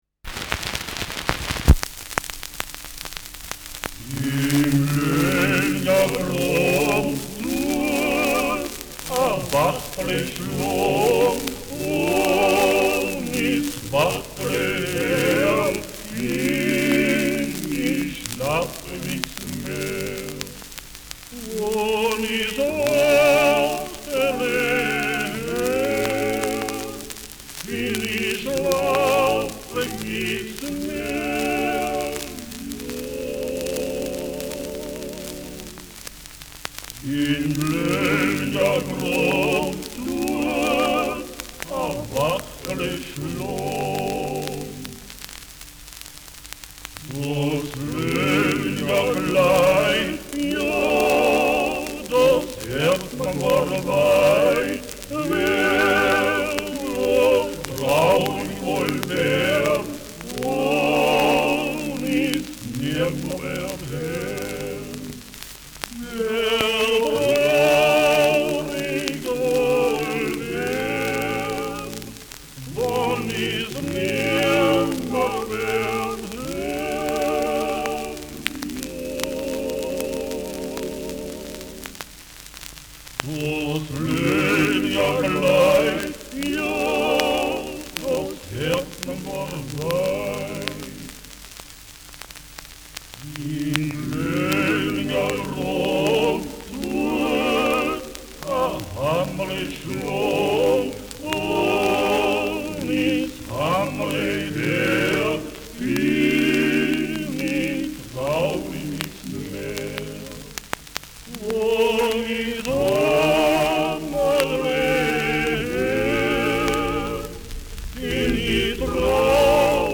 In Löllingergrab'n : Kärntner Volkslied [Im Löllinger Graben : Kärntener Volkslied]
Schellackplatte
Durchgehend stärkeres Knacken : Große Dynamik : Durchgehend oszillierender Pfeifton : Abgespielt : Grundrauschen im unteren Frequenzbereich
Villacher Männer-Gesangsverein, Quintett (Interpretation)